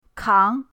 kang2.mp3